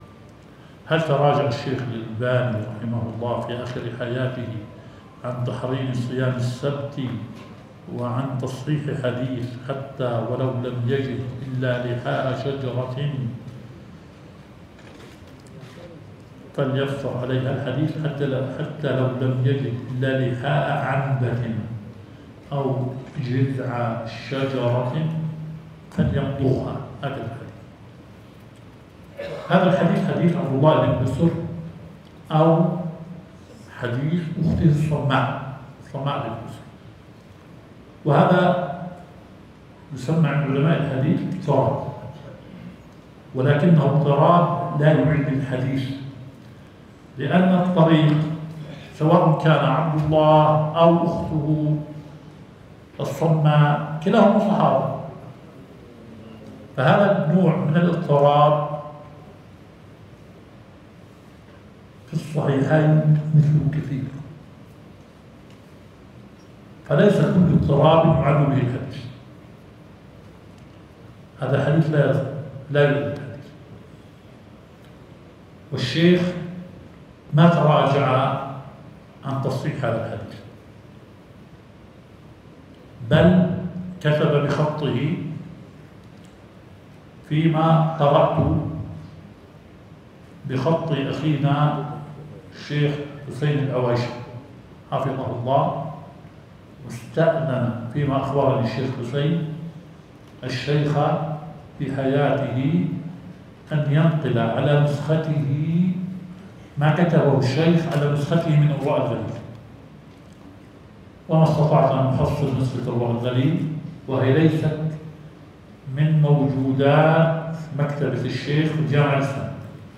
البث المباشر – لدرس شيخنا شرح صحيح مسلم